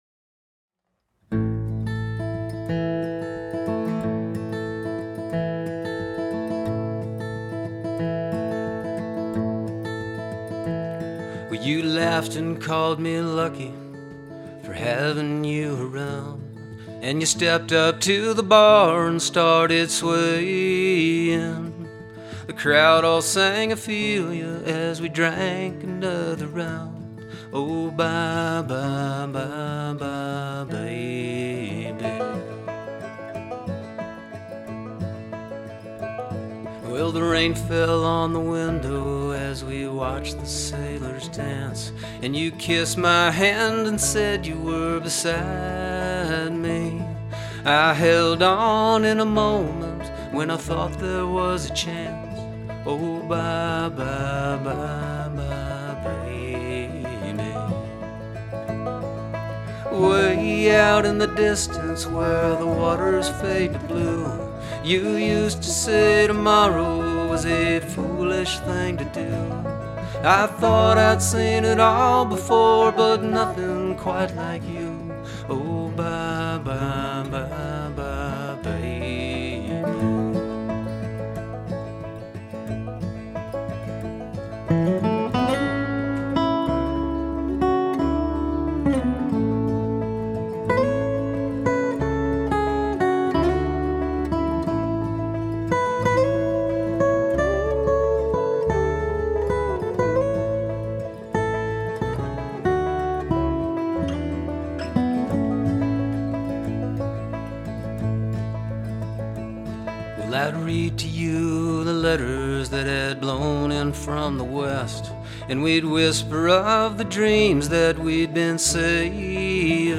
hi - this is my second project in recording (using reaper).